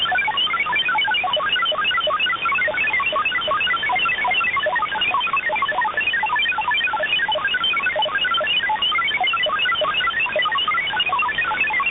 MFSKMultiple Frequency Shift-Keying-21-13 signal, MFSKMultiple Frequency Shift-Keying-21 part